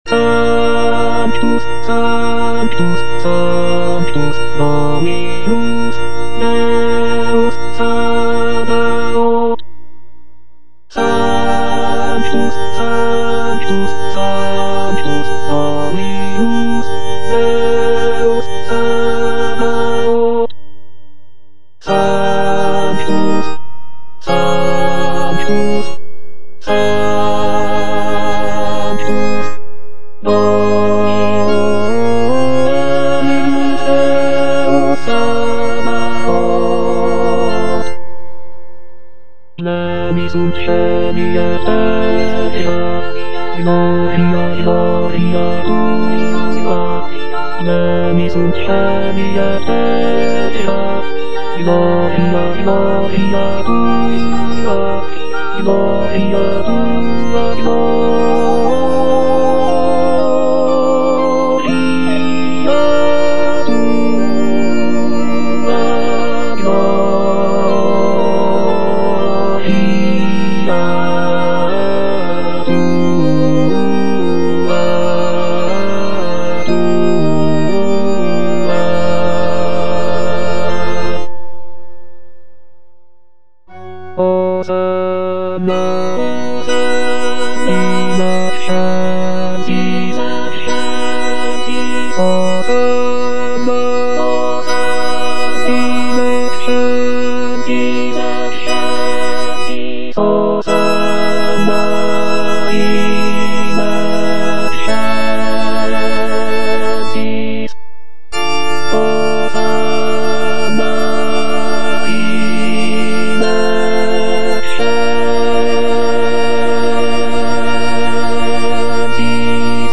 F. LISZT - MISSA CHORALIS S.10 Sanctus (bass I) (Emphasised voice and other voices) Ads stop: auto-stop
The piece features grand and dramatic choral sections, showcasing Liszt's mastery of choral writing.